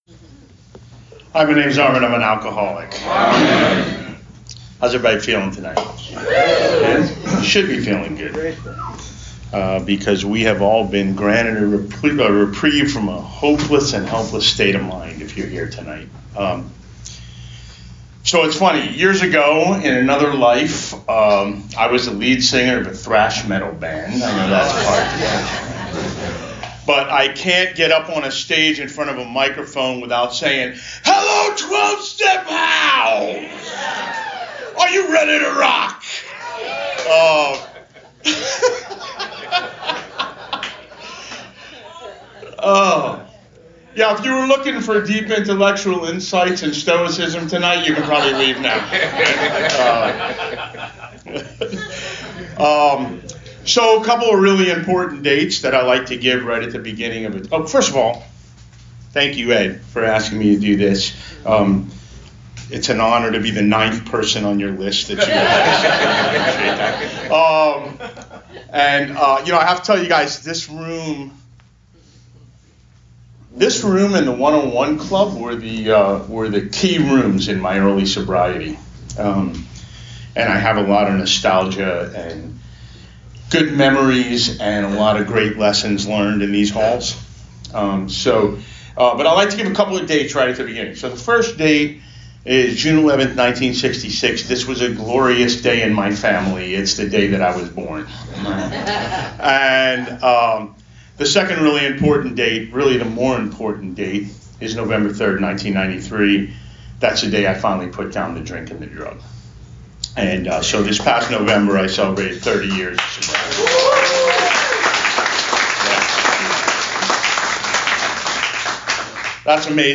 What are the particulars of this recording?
at the Old Timers Group, Fort Lauderdale 12 Step House